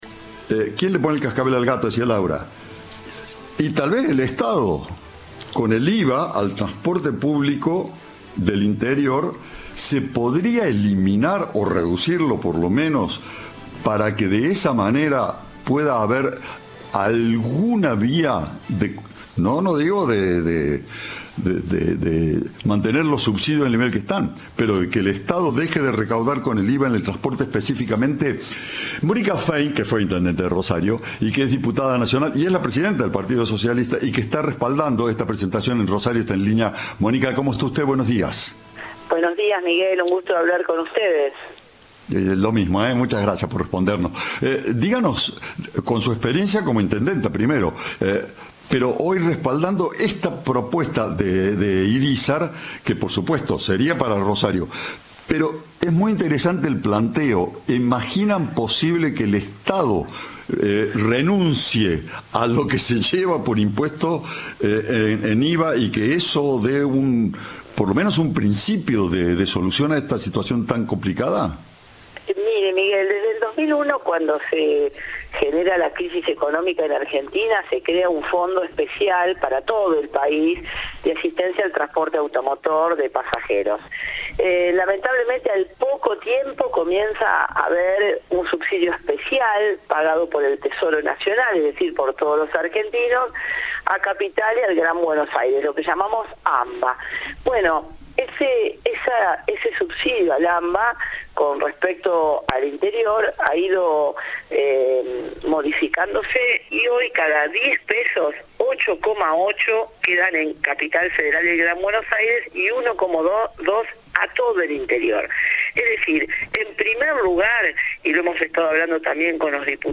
La diputada nacional y presidenta del Partido Socialista, Mónica Fein, habló con Cadena 3 sobre el proyecto presentado en el Congreso de la Nación por su par Enrique Estévez, para eximir del IVA (Impuesto al Valor Agregado) al transporte público del interior del país y bajar el precio del boleto.
Entrevista